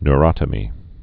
(n-rŏtə-mē, ny-)